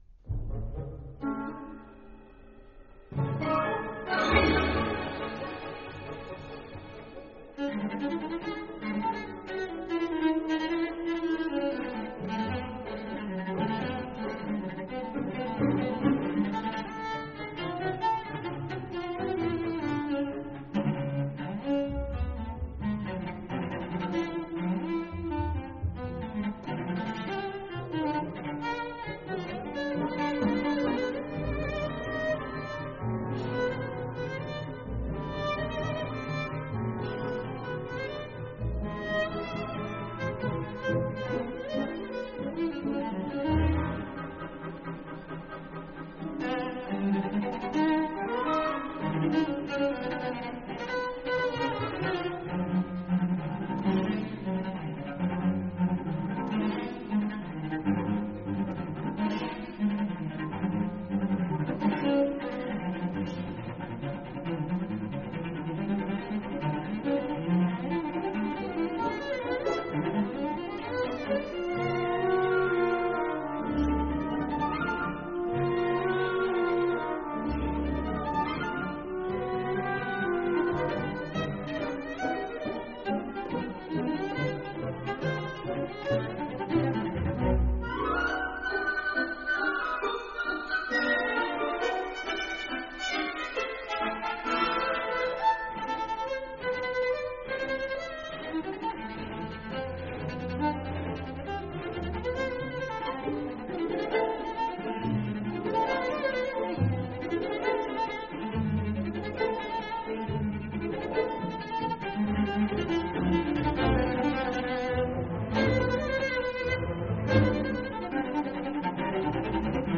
rich, atmospheric display vehicle